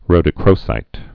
(rōdə-krōsīt)